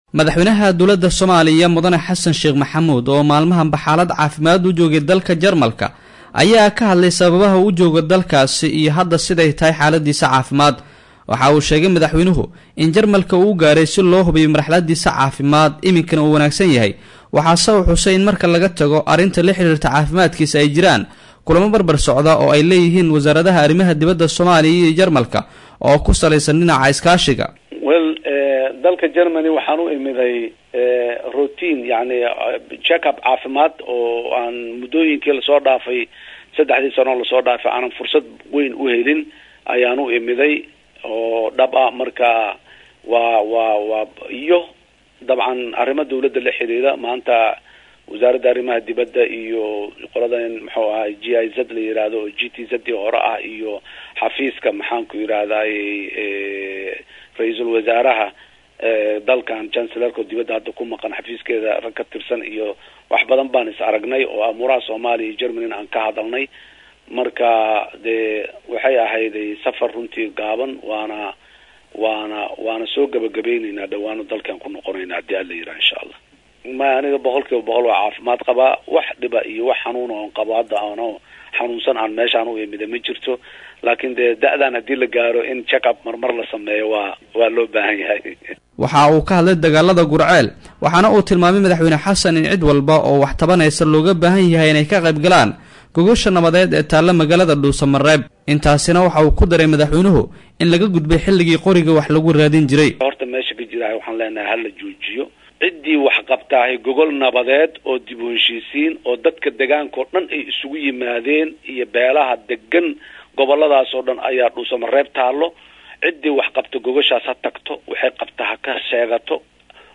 Xasan Sheekh Maxamuud oo Wareysi siiyay Idaacada BBC, ayaa wuxuu sheegay in arinta uu u tagay dalka Jarmalka ay tahay arimo la xiriira dhanka caafimaadkiisa, maadaama sida uu sheegay 3-dii sano ee u dambeysay uusan sameynin hubin caafimaad oo caafimaadkiisa ku saabsan.